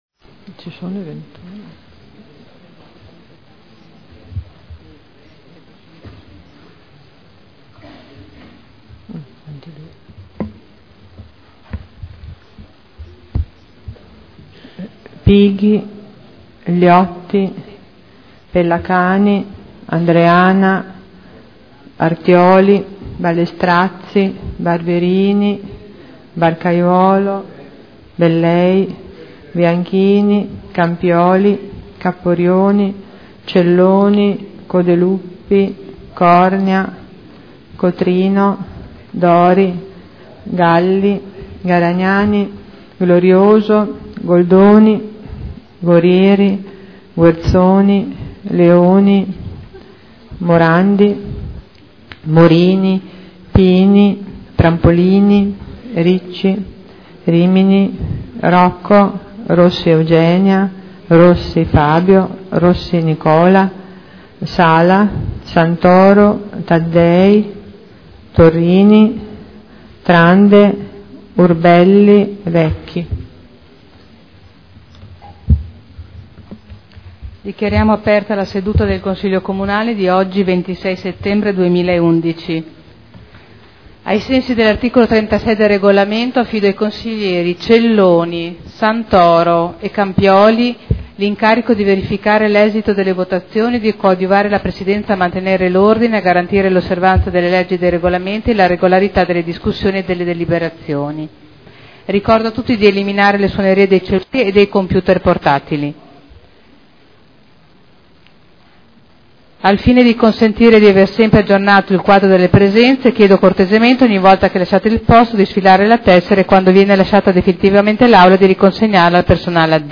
Seduta del 26 settembre 2011 Apertura del Consiglio Comunale Appello e minuto di silenzio in memoria di Wangari Maathai premio nobel della pace